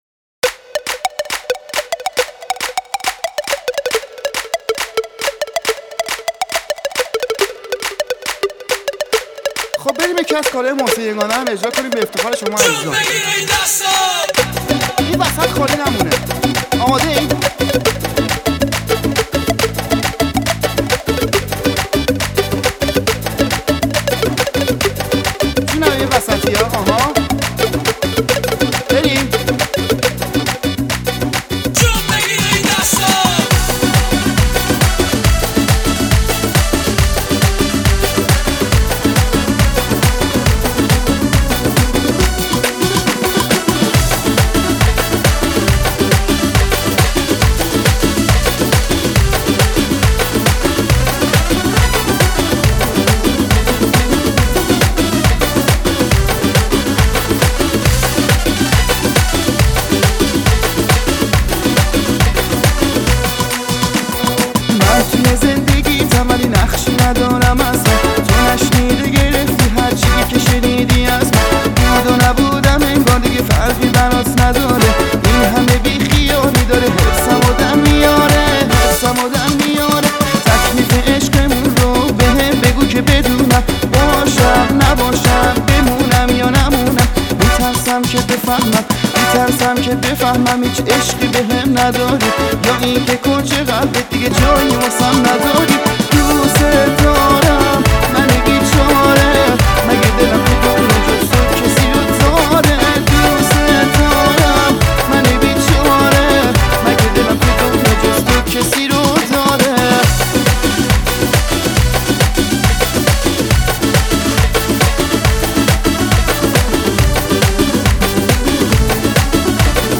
آهنگ ارکستی